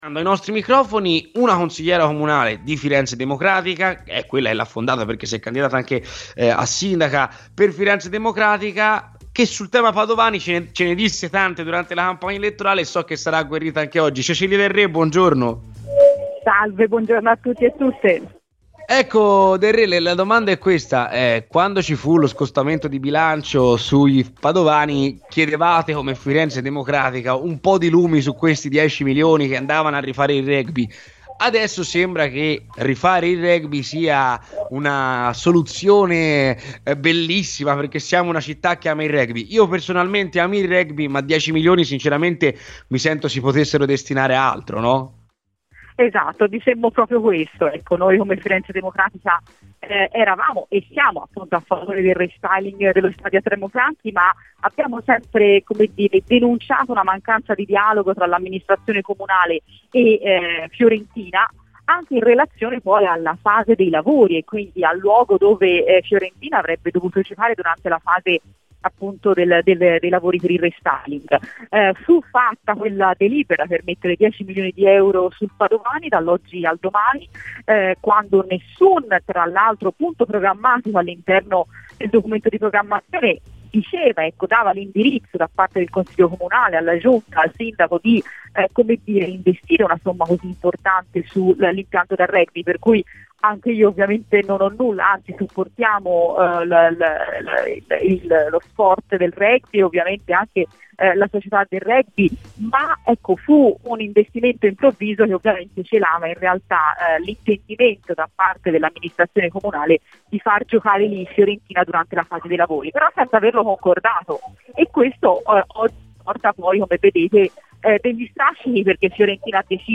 La Consigliera Comunale e presidente di Firenze Democratica Cecilia Del Re è intervenuta stamani a Radio Firenzeviola, durante 'C'è polemica'.